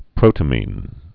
(prōtə-mēn, -mĭn) also pro·ta·min (-mĭn)